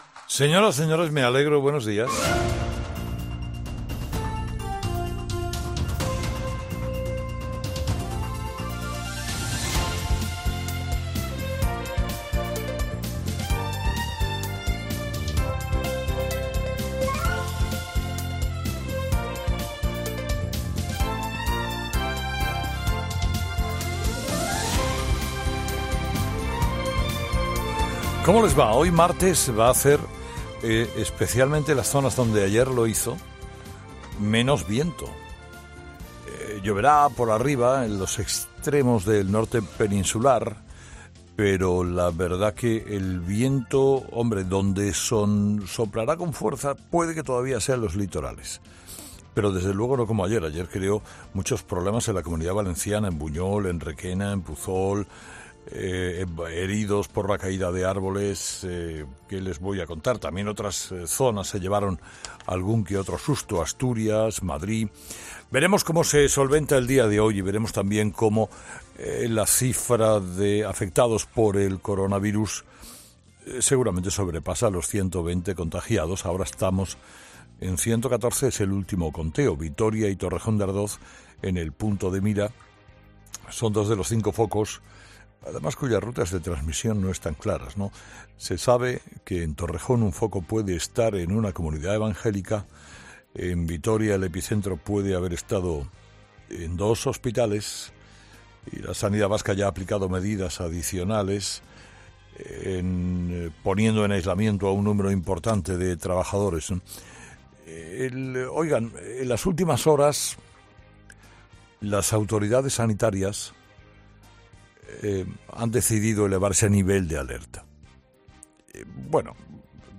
En su monólogo de esta mañana Carlos Herrera hace referencia a la Ley de Educación, concretamente en las medidas del Gobierno en la educación concertada.